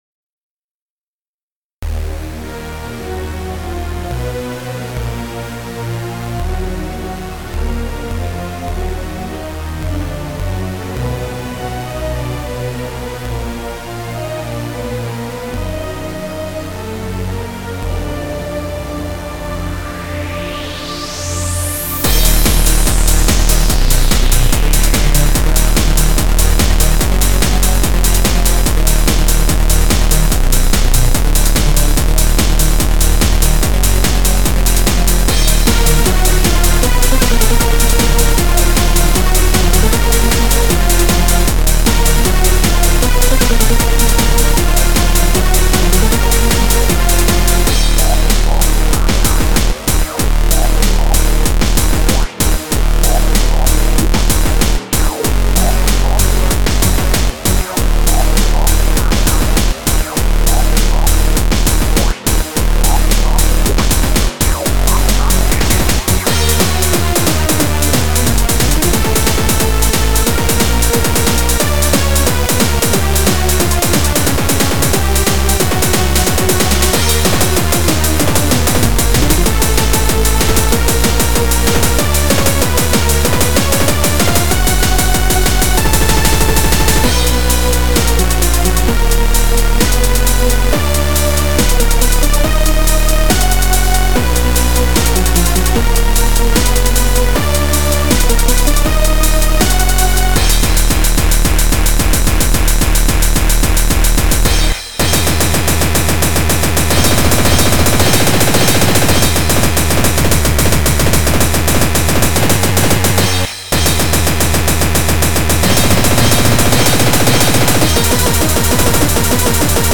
BPM20-999
Genre: Hardcore Mixture